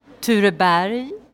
pronunciation) is a neighbourhood in Sollentuna Municipality, Stockholm County, Sweden with 18,866 inhabitants (2018).[1] It is a Stockholm suburb and houses the seat of local government for the municipality as well as a large shopping mall and a Stockholm commuter rail station.
Sv-Tureberg.ogg.mp3